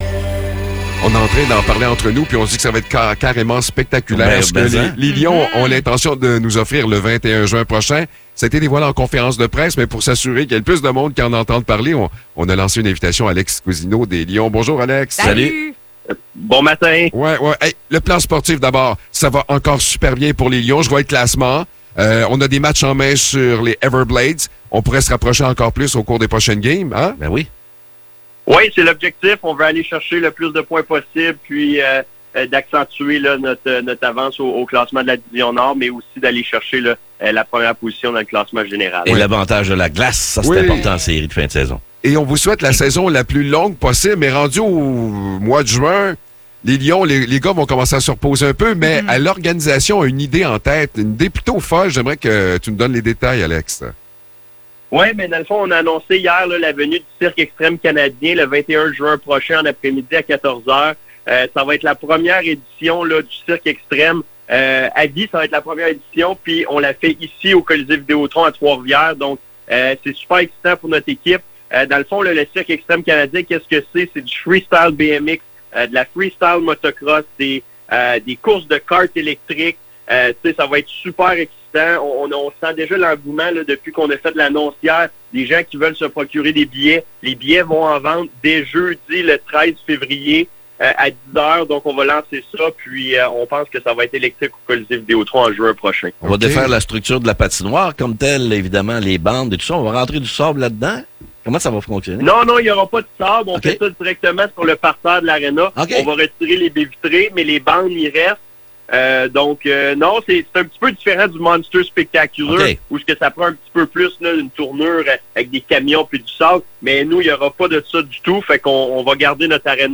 Entrevue pour les Lions de Trois-Rivières